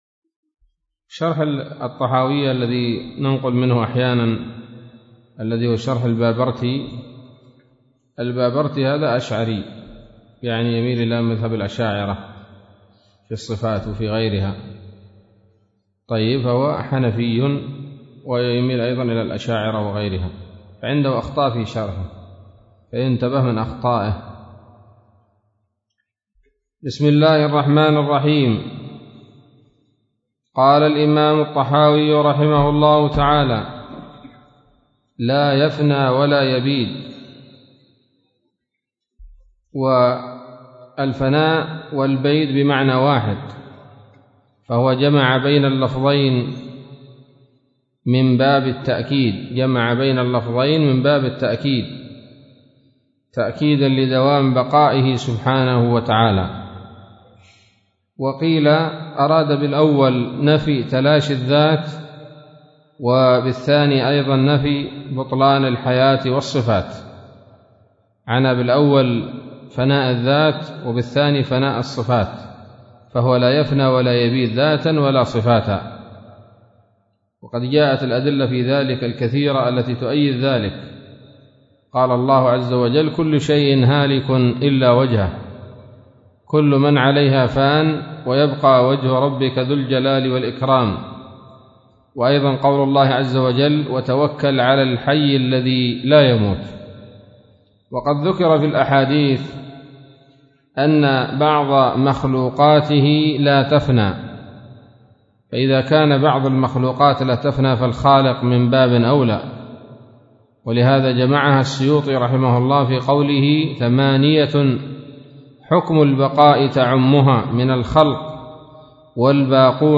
الدرس الرابع من شرح العقيدة الطحاوية